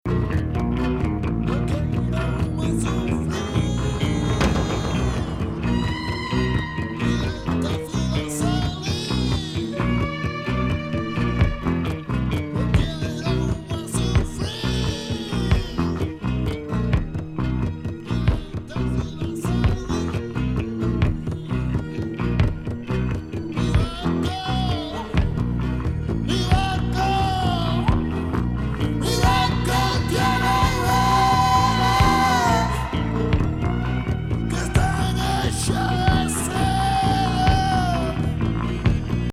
スペイン産プログレ・グループ